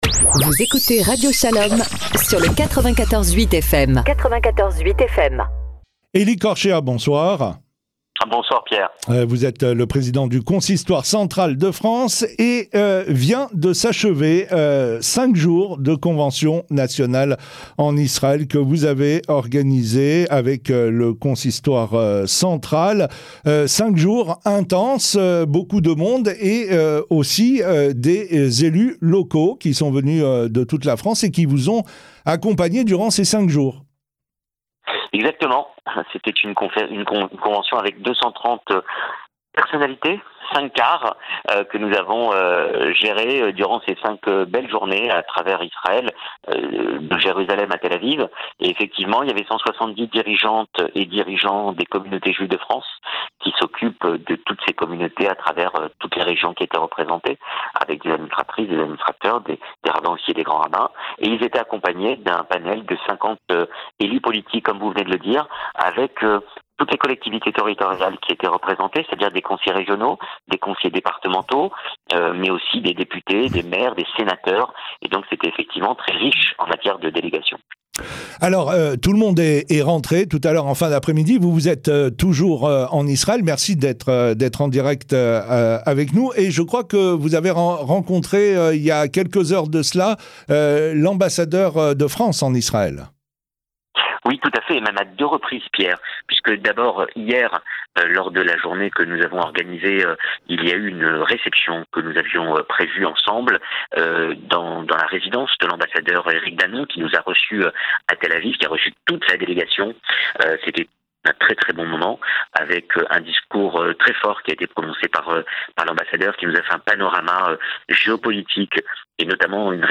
interviewé depuis Tel-Aviv